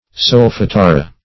Search Result for " solfatara" : The Collaborative International Dictionary of English v.0.48: Solfatara \Sol`fa*ta"ra\, n.[It., from solfo brimstone, sulphur, L. sulfur, E. sulphur.] (Geol.) A volcanic area or vent which yields only sulphur vapors, steam, and the like.